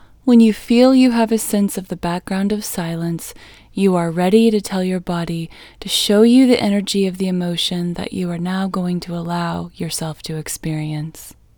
LOCATE Short OUT English Female 8